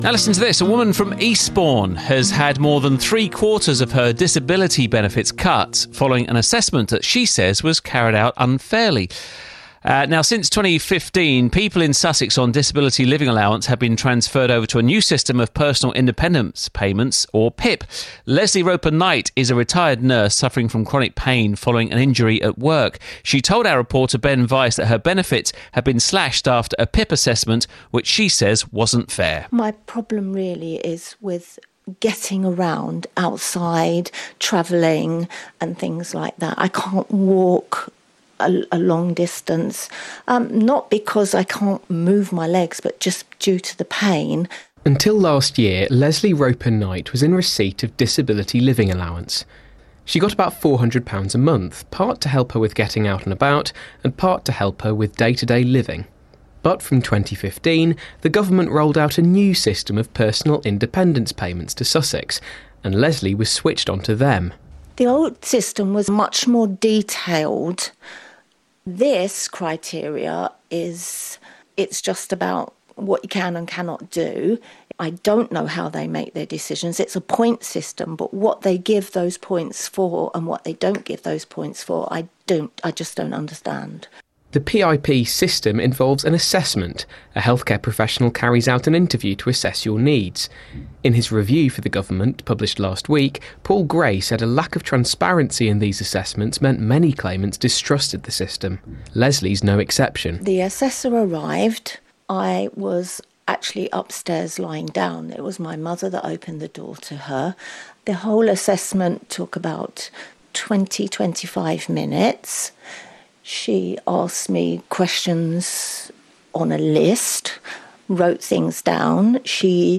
• Disability Benefits – public event
Eastbourne Town Hall
The event was covered by BBC Sussex Radio and you can listen to two clips here: